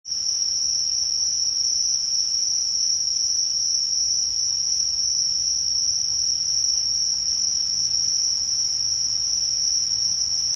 秋の声が学校のまわりからも聞こえてきましたね 虫の声暑かった夏が懐かしく感じるなぁ〜